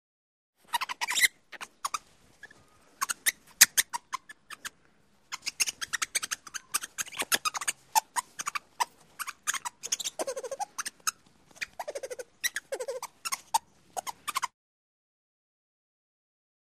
Lemur Chirps. High Pitched, Rapid, Squeaky Chirps. Close Perspective.